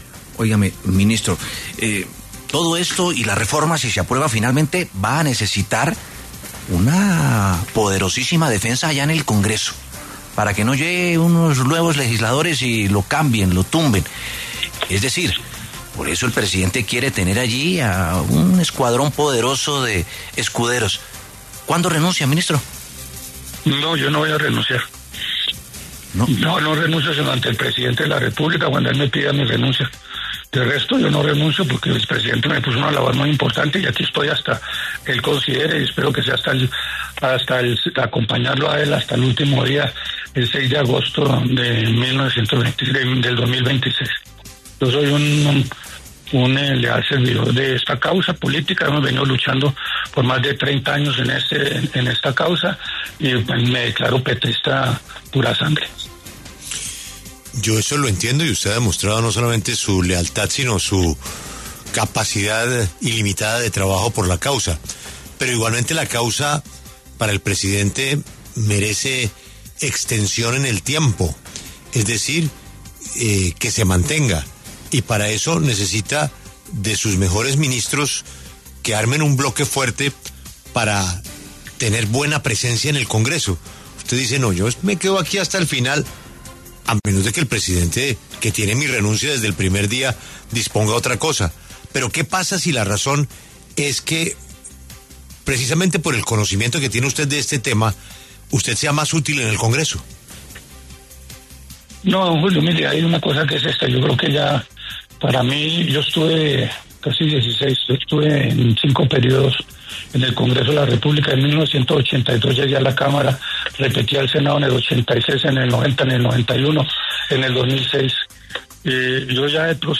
El ministro de Salud, Guillermo Alfonso Jaramillo, en entrevista con La W, recalcó que no va a renunciar a menos que el presidente Petro le pida el cargo.